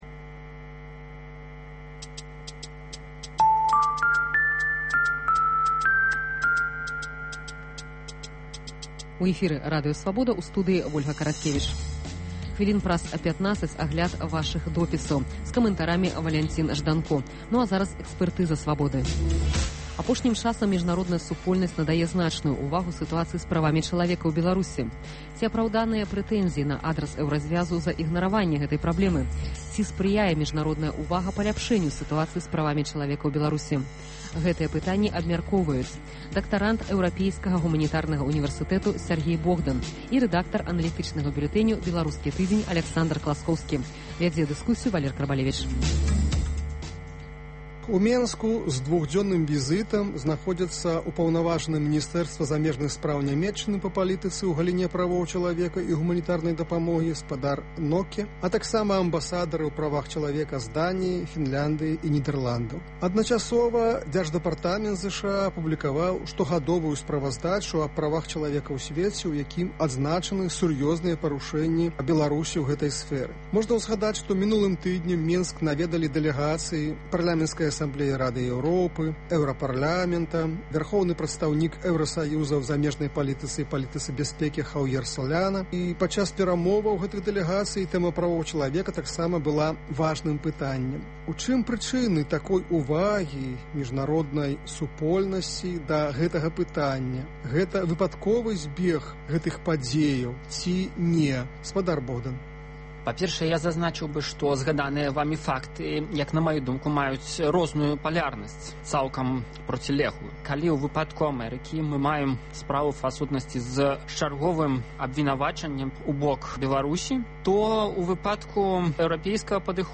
Ці садзейнічае міжнародная ўвага паляпшэньню сытуацыі з правамі чалавека ў Беларусі? Гэтыя пытаньні абмяркоўваюць за круглым сталом